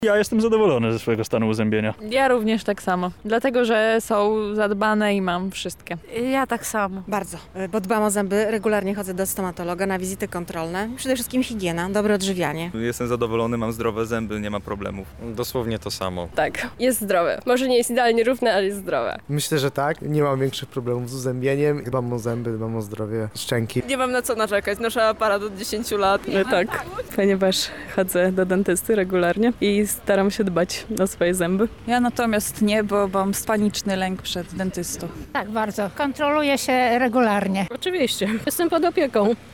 Zapytaliśmy mieszkańców Lublina czy są zadowoleni ze stanu swojego uzębienia.
Sonda o zębach
Sonda-o-zebach1.mp3